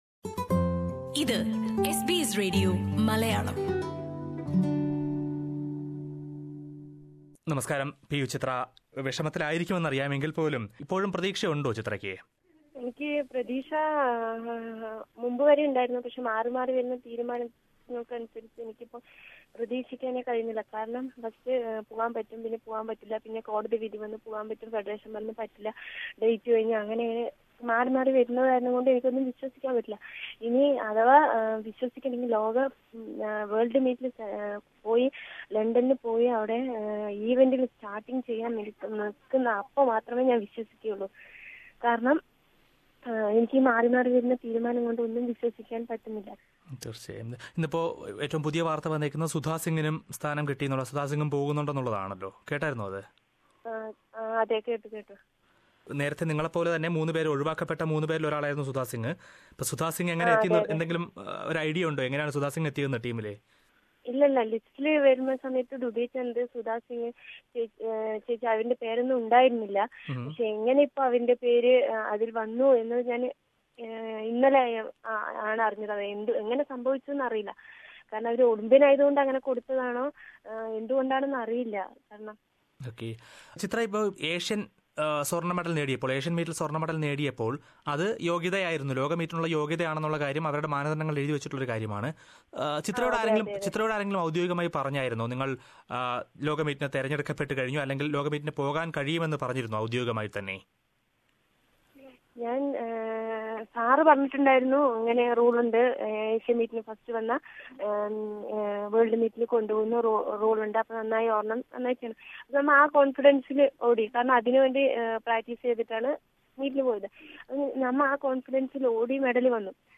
ഏഷ്യൻ അത്ലറ്റിക് മീറ്റിൽ സ്വർണ മെഡൽ ജേതാവായ പി യു ചിത്രയെ ലോക അത്ലറ്റിക് മീറ്റിലേക്കുള്ള ഇന്ത്യൻ ടീമിൽ നിന്ന് ഒഴിവാക്കിയതോടെ ലോകമെമ്പാടുമുള്ള മലയാളികൾ പി യു ചിത്രക്ക് പിന്തുണയുമായി രംഗത്തെത്തിയിരിക്കുകയാണ്. ടീമിൽ നിന്ന് ഒഴിവാക്കപ്പെട്ടതിനെക്കുറിച്ചും, ഭാവി പ്രതീക്ഷകളെക്കുറിച്ചുമൊക്കെ പി യു ചിത്ര തന്നെ എസ് ബിഎസ് മലയാളത്തോട് സംസാരിക്കുന്നത് കേൾക്കാം മുകളിലെ പ്ലേയറിൽ നിന്ന്...